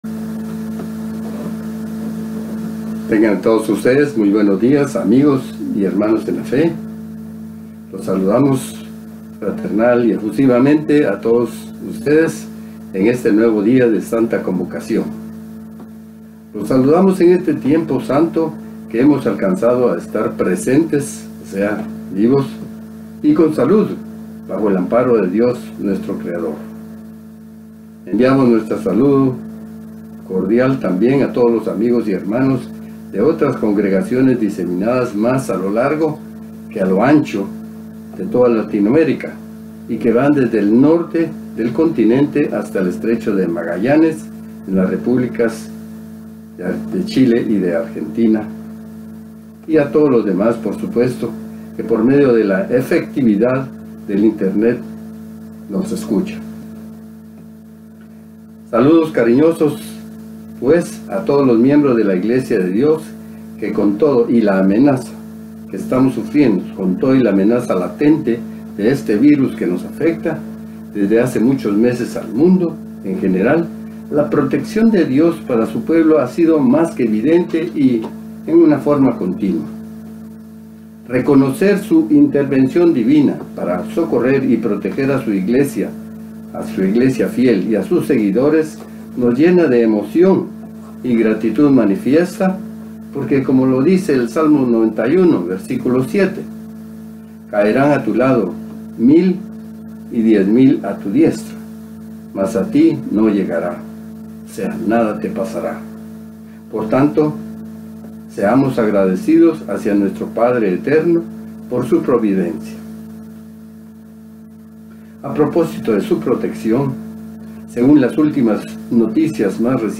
¿En qué debemos poner nuestra mira? No en el mundo. Mensaje entregado el 21 de noviembre de 2020.